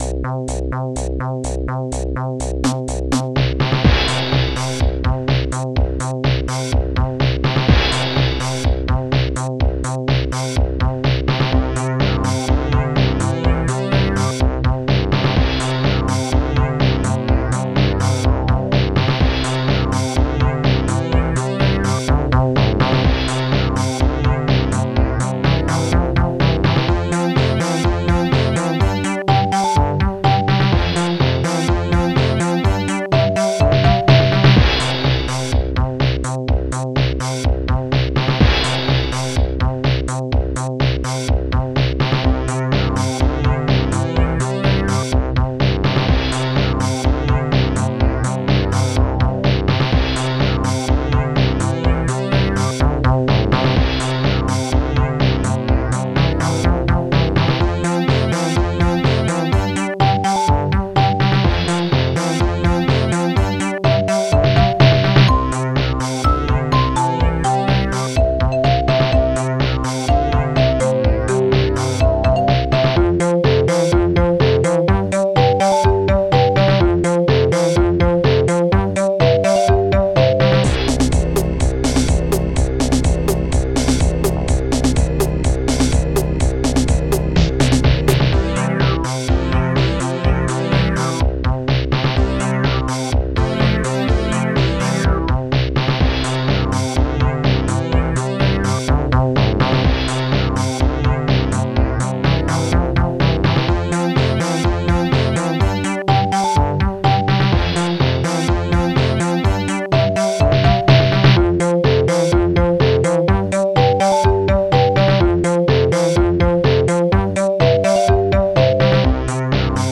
Protracker Module